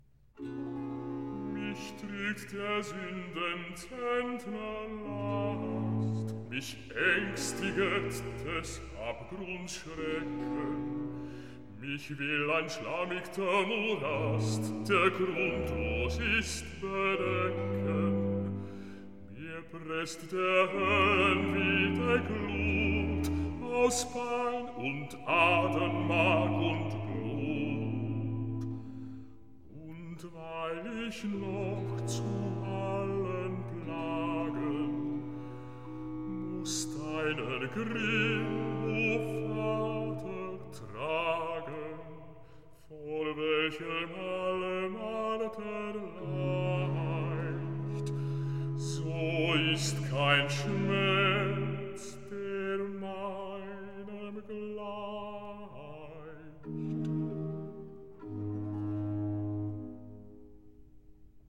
Recitativo